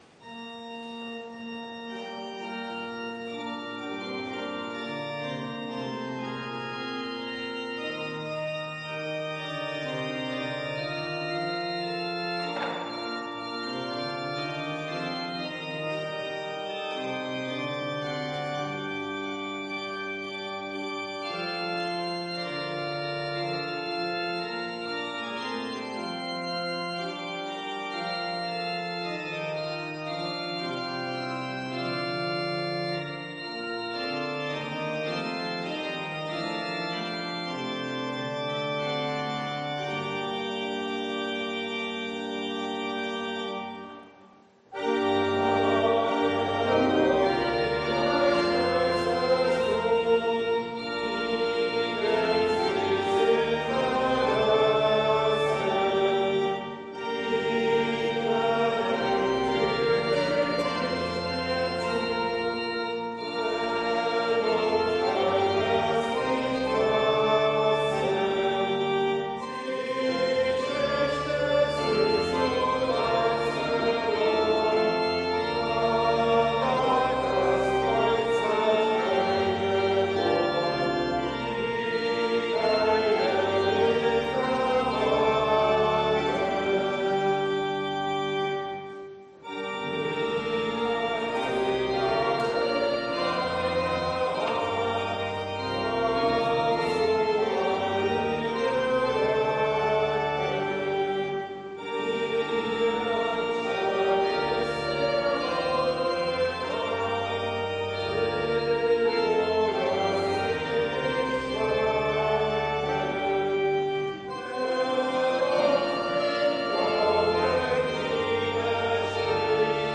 Audiomitschnitt unseres Gottesdienstes vom 17. Sonntag nach Trinitatis 2022.